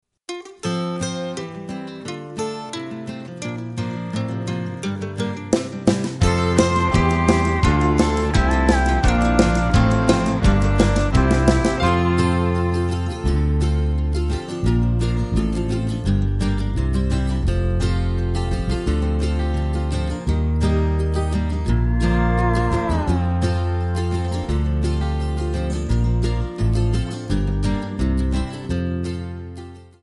E/F#
MPEG 1 Layer 3 (Stereo)
Backing track Karaoke
Country, 2000s